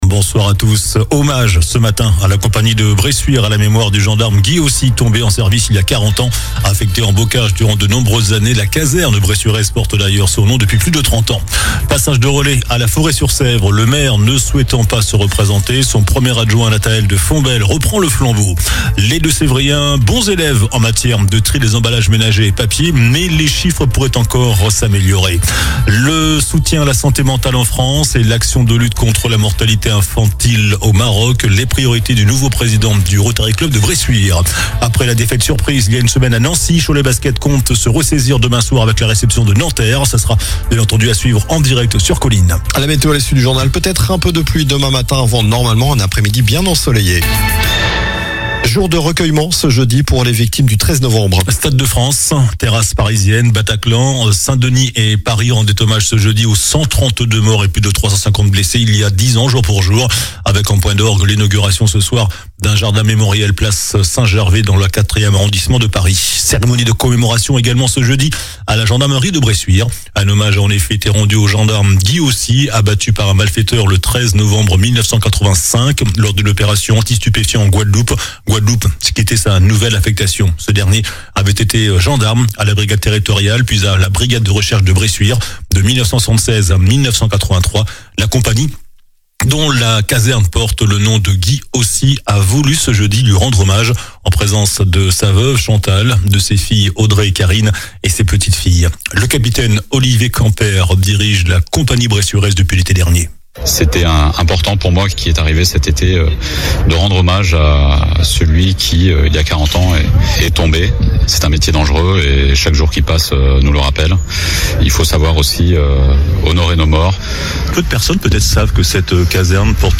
COLLINES LA RADIO : Réécoutez les flash infos et les différentes chroniques de votre radio⬦
JOURNAL DU JEUDI 13 NOVEMBRE ( SOIR )